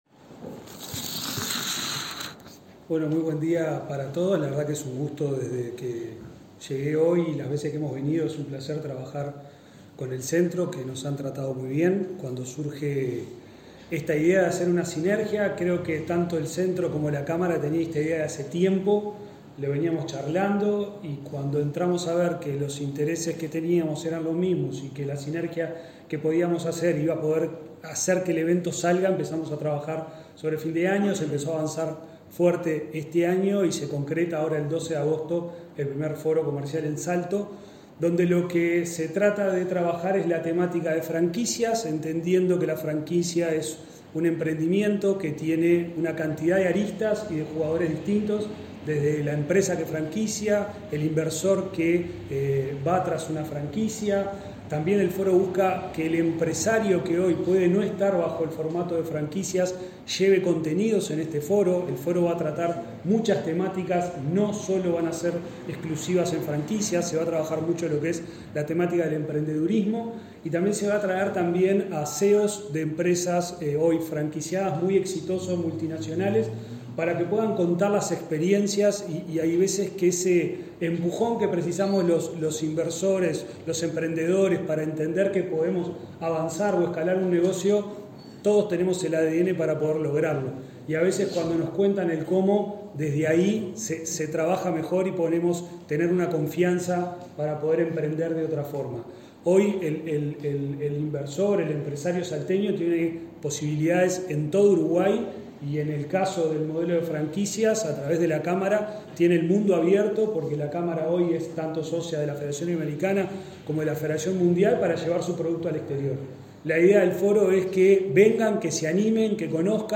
Este martes 29 de junio se realizó la conferencia de prensa de lanzamiento del Foro de Negocios Salto 2025, que se llevará a cabo el próximo 12 de agosto en el Centro Comercial e Industrial.